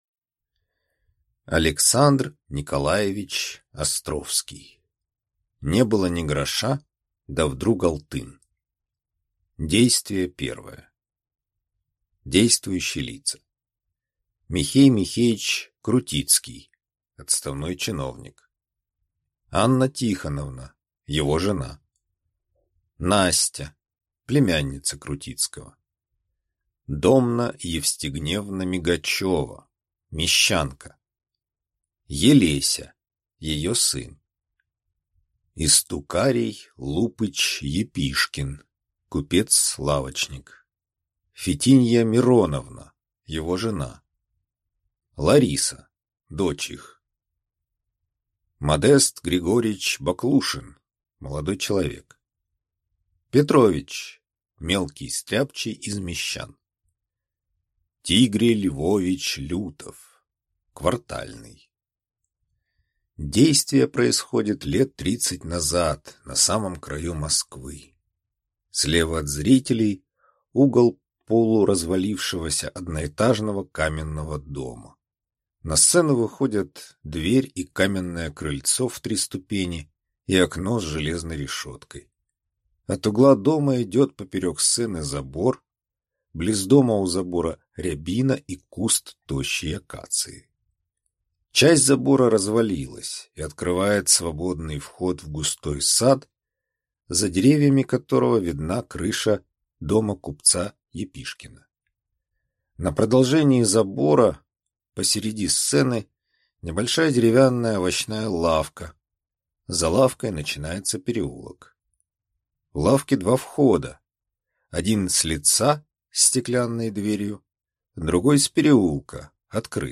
Аудиокнига Не было ни гроша да вдруг алтын | Библиотека аудиокниг
Aудиокнига Не было ни гроша да вдруг алтын Автор Александр Островский